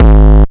shallow kick.wav